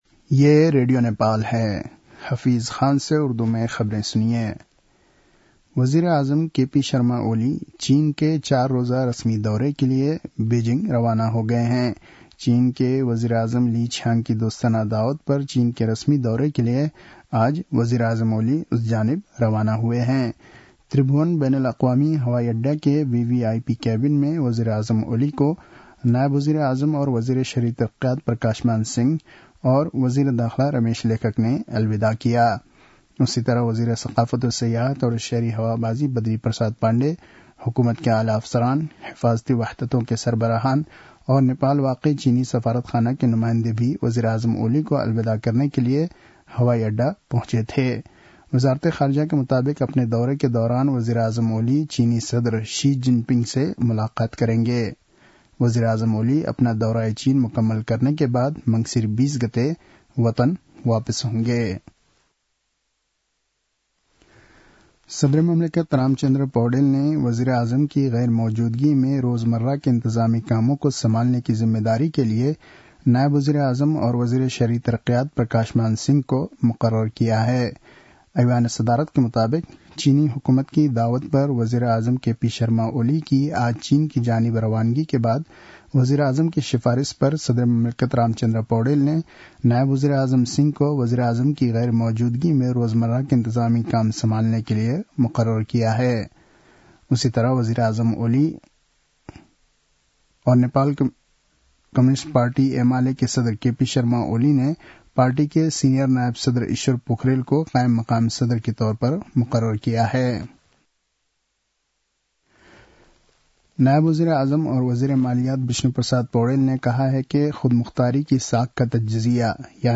उर्दु भाषामा समाचार : १८ मंसिर , २०८१